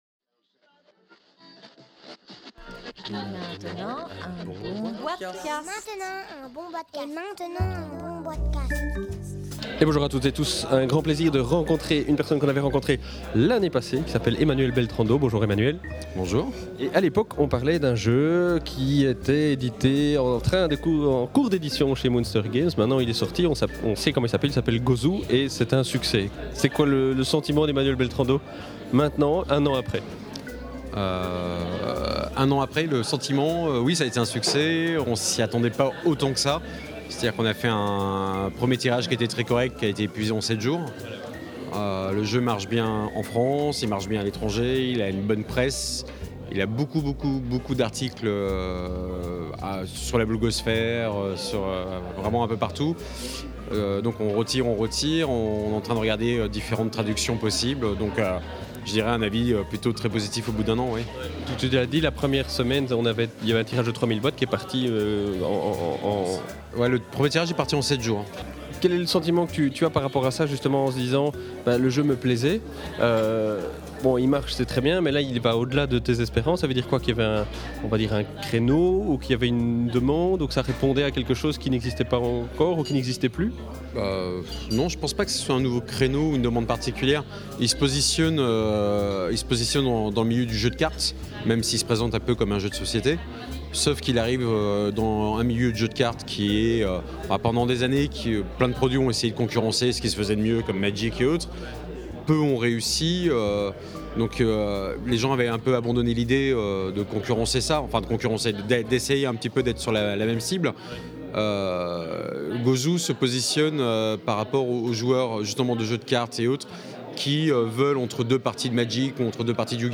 (enregistré au Salon international du Jeu de Société de Essen – Octobre 2010)